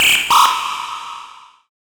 OLDRAVE 2 -R.wav